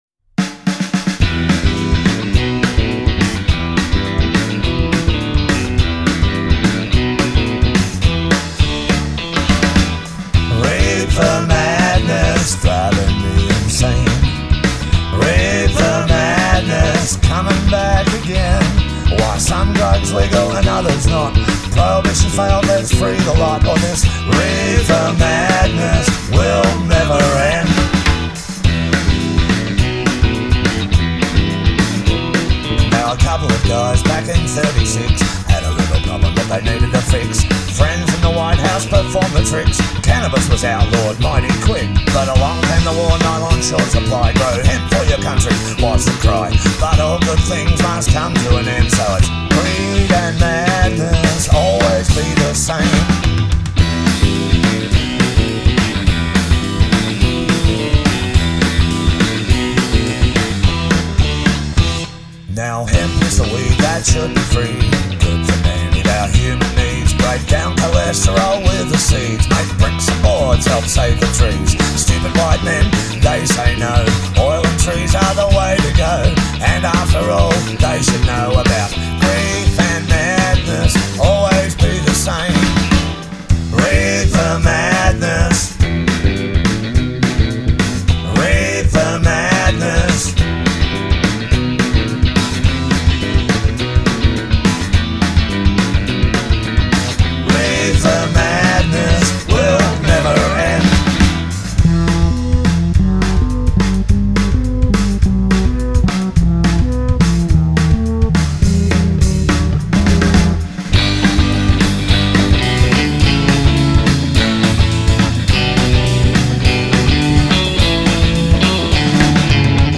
Rhythm and Blues